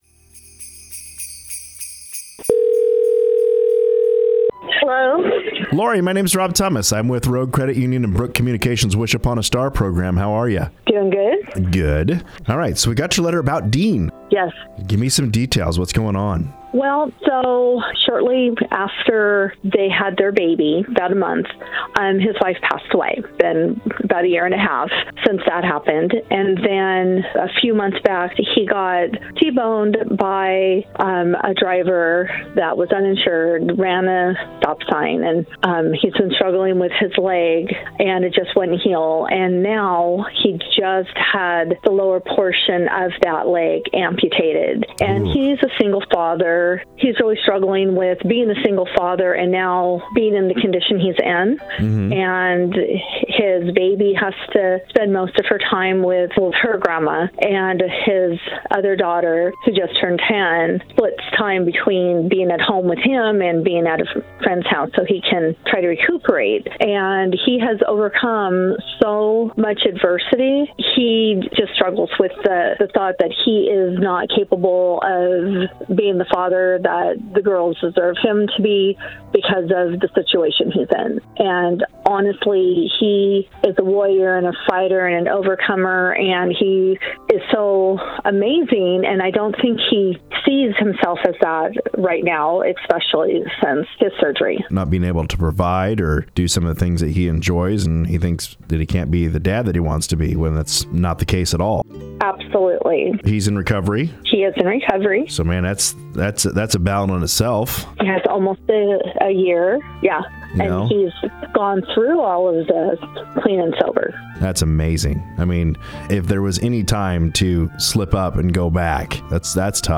talks with a veteran in need of some reassurance and inspiration.